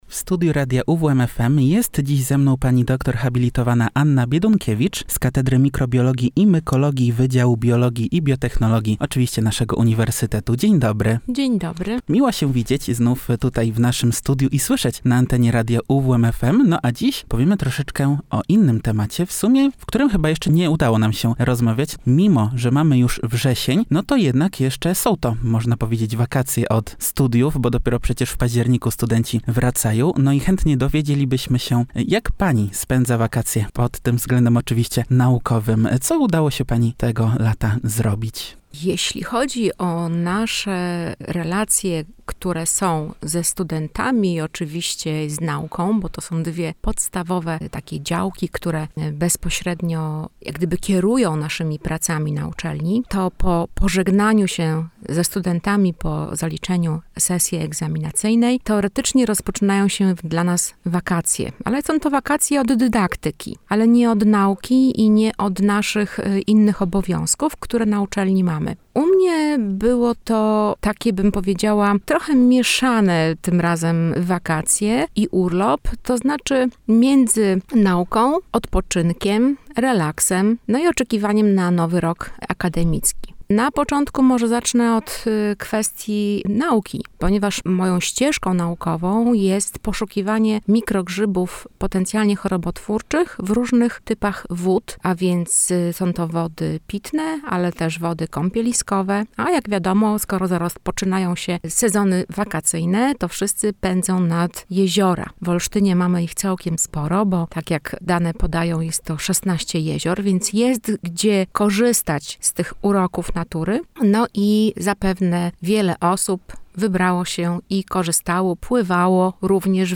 W naszym radiowym studiu oprócz opowieści o naukowych zadaniach, nie zabrakło też najważniejszych rad i zasad, o których warto pamiętać wybierając się do lasu na grzyby.